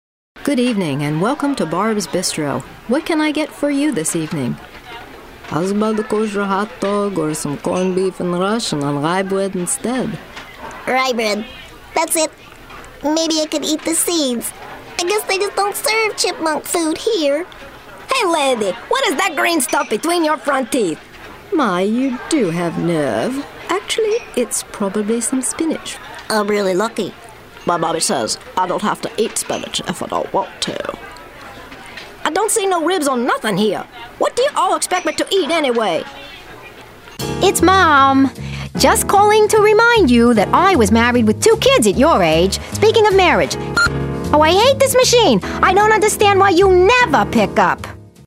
Character voices, foreign accents, narration, American dialects, children's voices, excellent imitative skills
Character Demo